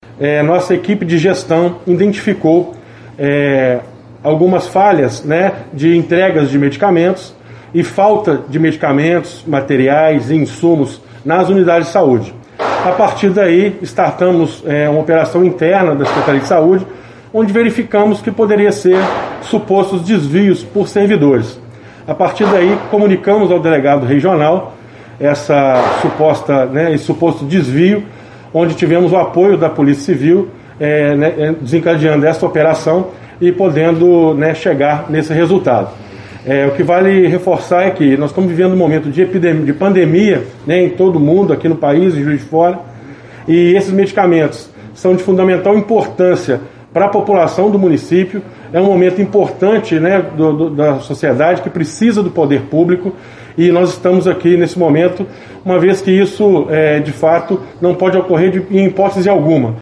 O secretário de saúde, Rodrigo Almeida, informou que as investigações tiveram início quando insumos e equipamentos começaram a faltar, o que gerou desconfiança dos gestores.
secretario-saude-rodrigo-desvio.mp3